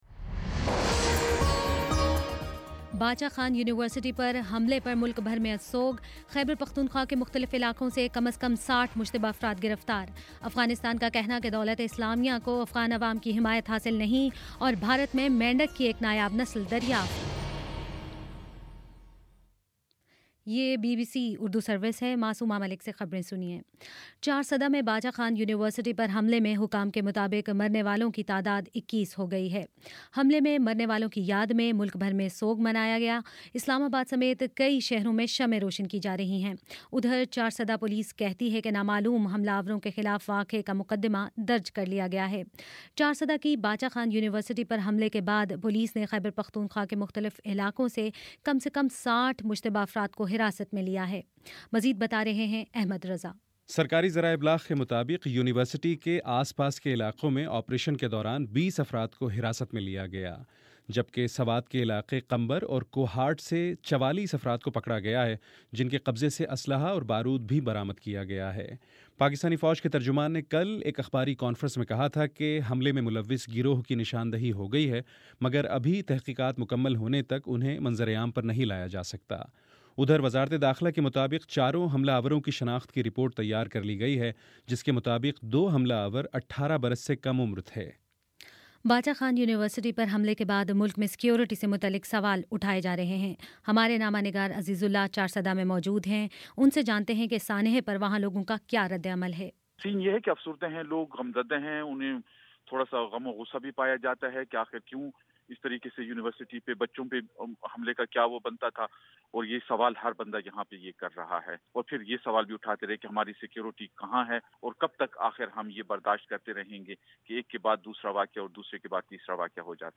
جنوری 21 : شام چھ بجے کا نیوز بُلیٹن